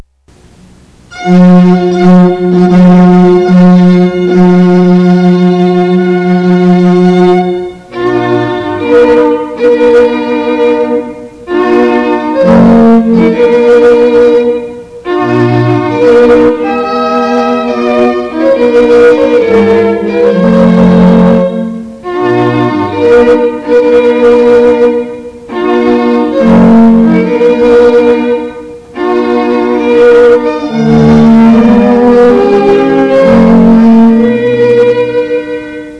Serenade String Quartet
Romantic Period Music Samples